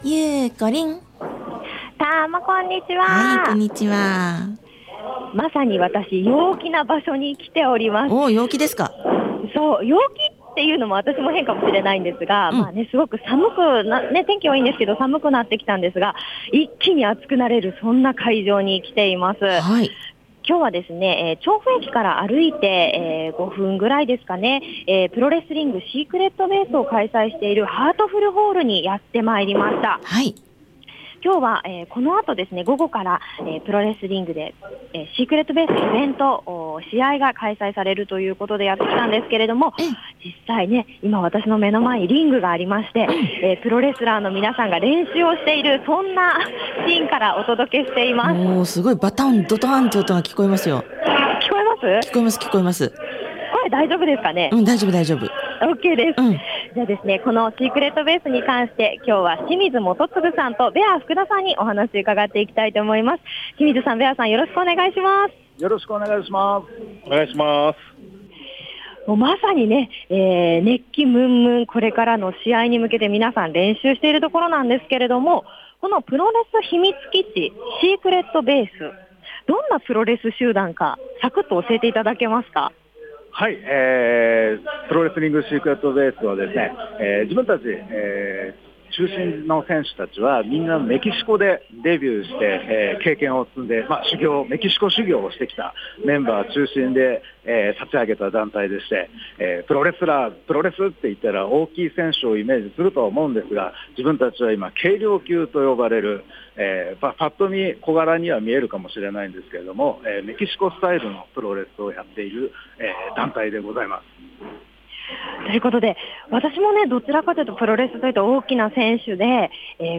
今週の日曜日も晴れましたね～♪ 11月も中盤にさしかかり、寒くなってきましたが、今日はそんな寒さも吹き飛ばす 調布駅より徒歩7分、調布はあとふるホールで「プロレス秘密基地 SECRET BASE」が開催されるという事で、 試合前のリングサイドからレポートお届けしましたよ～！